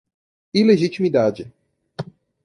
Feminino ilegitimidade a 🐌 Significado (Inglés) illegitimacy Traducións illegitimacy Pronúnciase como (IPA) /i.le.ʒi.t͡ʃi.miˈda.d͡ʒi/ Etimoloxía (Inglés) From i- + legitimidade.